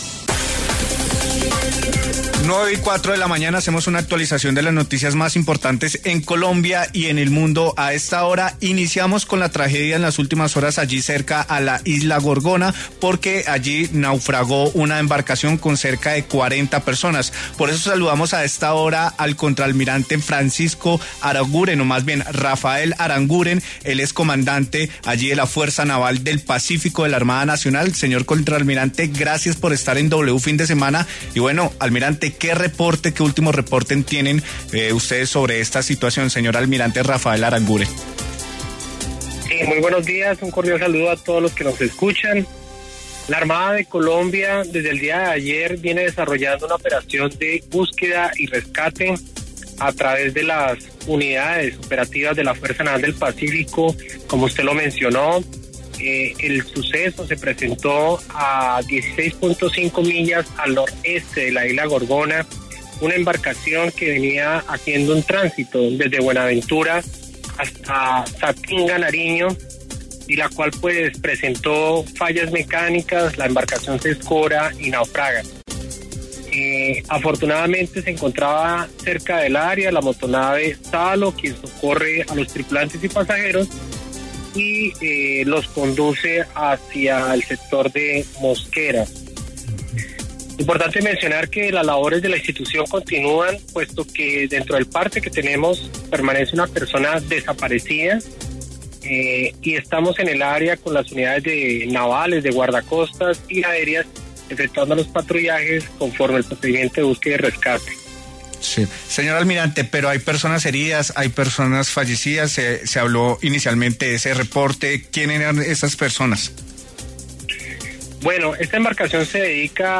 El comandante de la Fuerza Naval del Pacífico, el contralmirante Rafael Aranguren, dijo en W Fin De Semana que “la embarcación se dedicaba al transporte de pasajeros”.